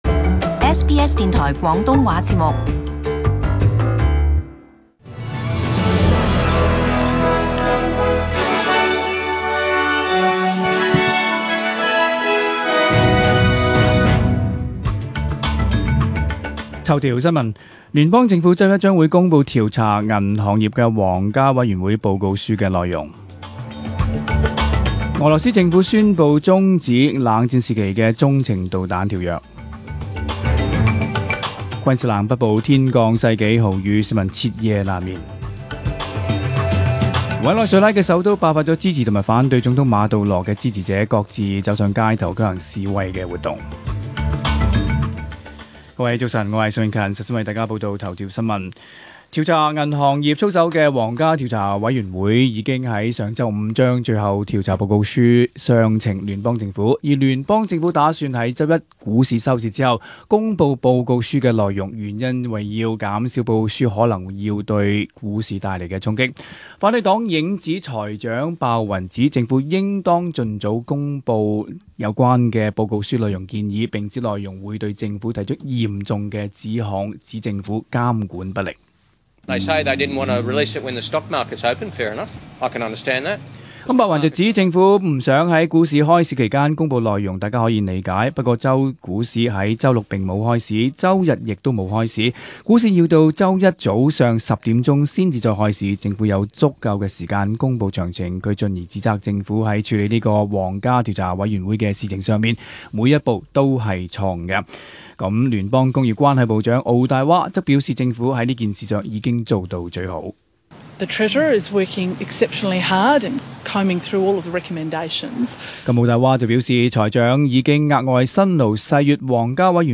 SBS中文新聞 （二月三日）
請收聽本台為大家準備的詳盡早晨新聞。 頭條新聞 1. 政府週一公佈調查銀行業皇家委員會報告書内容 2. 俄羅斯政府宣佈中止冷戰時期的中程導彈條約 3. 昆士蘭北部天降世紀豪雨、市民徹夜難眠。